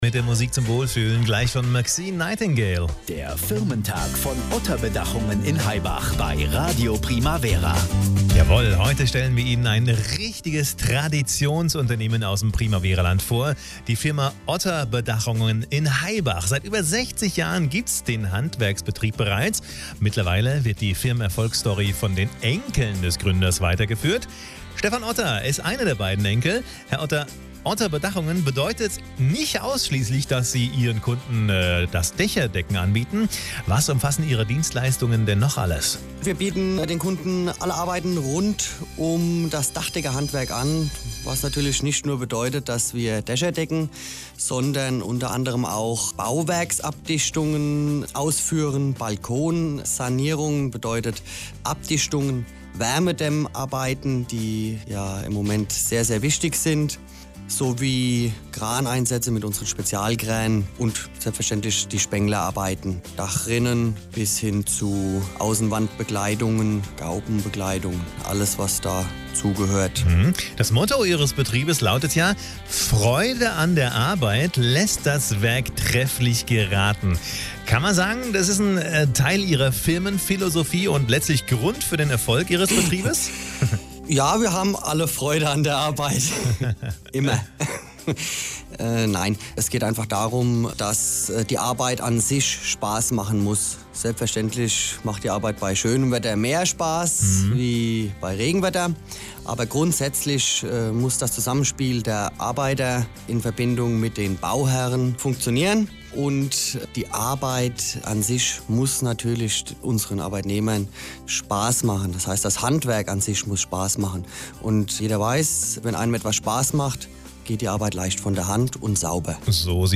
Interview beim Firmentag von Radio Primavera